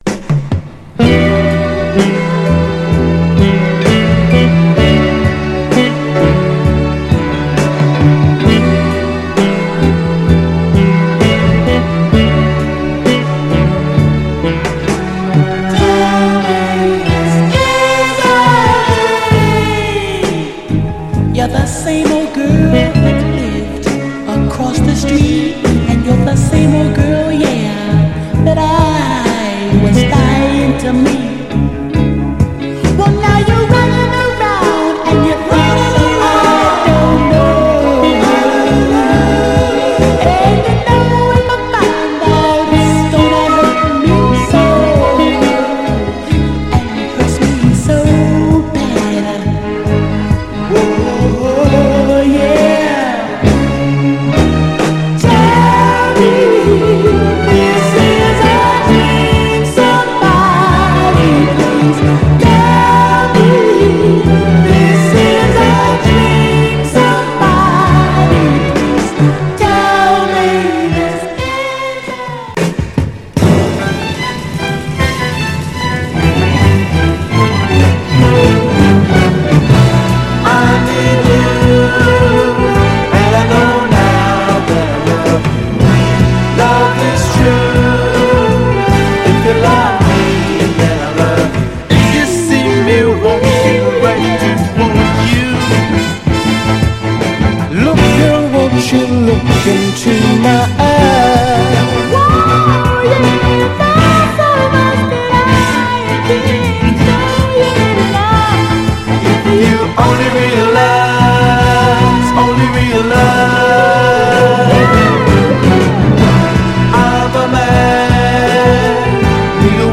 切ないスウィート・ソウル
盤はいくつか細かいヘアーラインキズ箇所ありますが、グロスがありプレイ良好です。
※試聴音源は実際にお送りする商品から録音したものです※